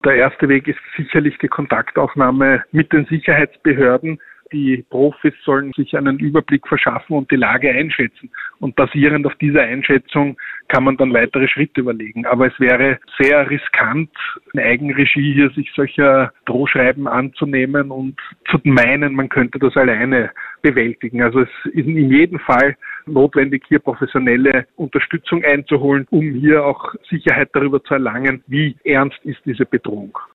Terrorexperte erklärt, was es mit Drohungen auf sich hat